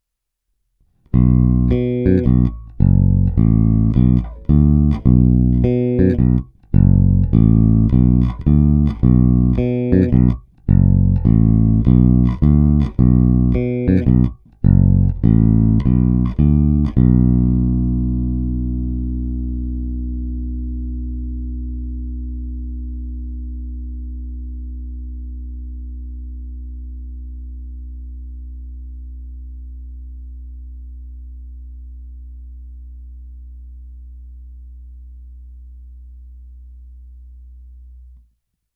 Kombinace snímačů opět takříkajíc vybočuje z řady svým projevem, je opět svá, lehce v ní zaznívá takový ten moderní nádech, alespoň já to tak vnímám.
Ukázky jsou nahrány rovnou do zvukové karty a jen normalizovány.
Oba snímače